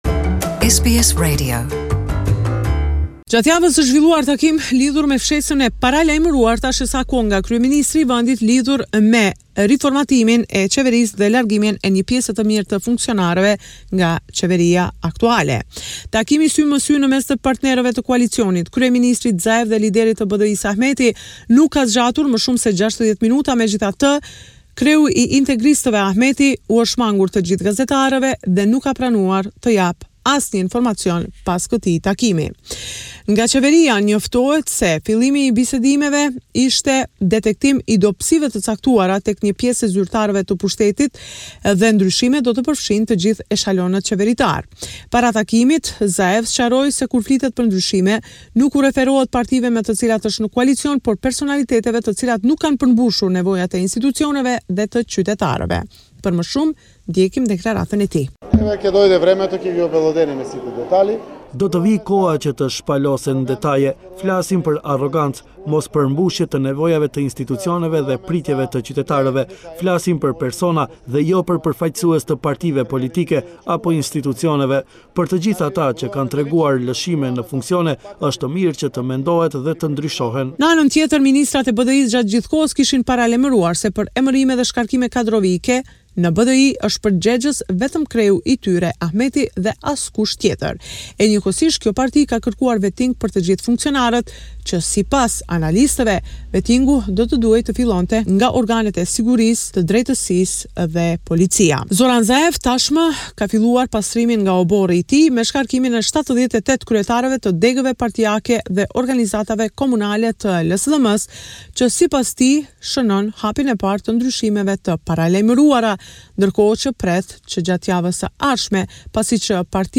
This is a report summarising the latest developments in news and current affairs in Macedonia.